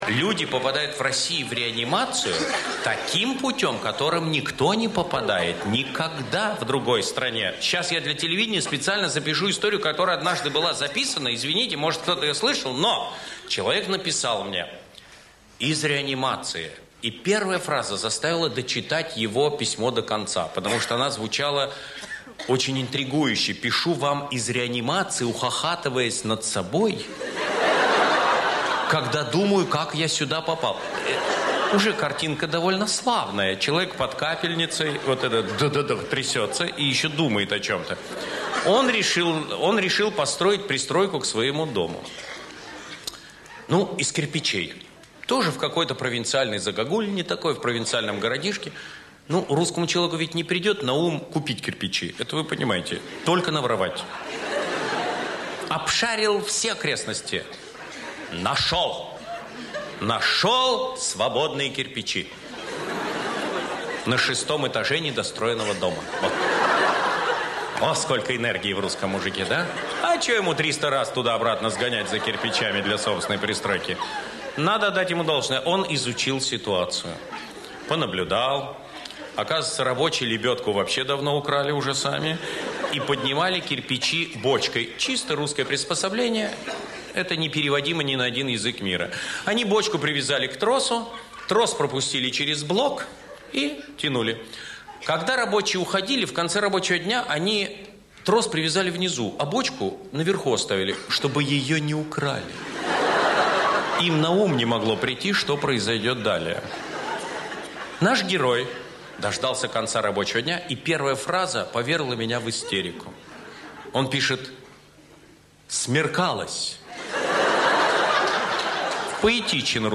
Юмор. Полный.